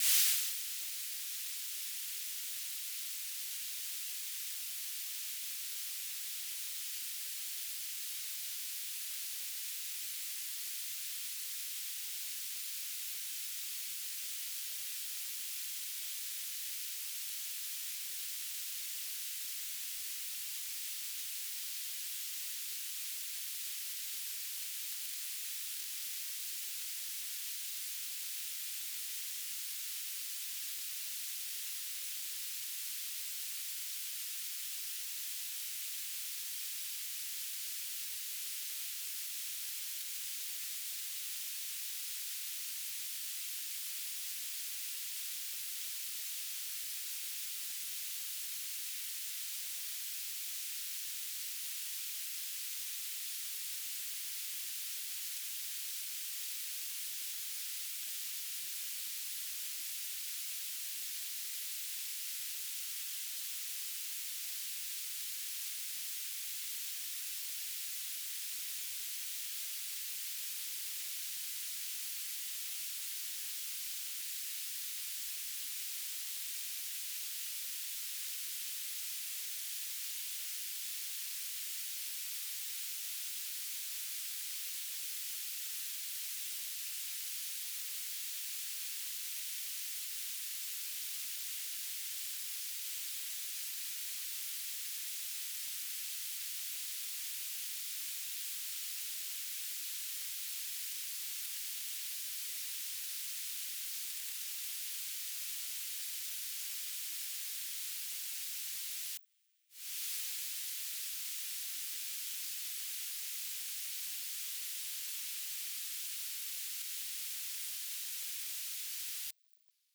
"transmitter_description": "Mode U - BPSK1k2 - Beacon",
"transmitter_mode": "BPSK",